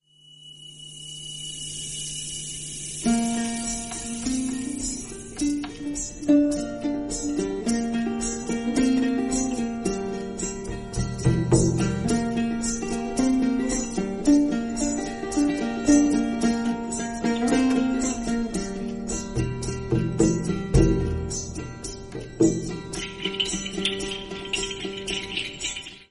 doudouk
kakak kemane
Enregistré sur son home studio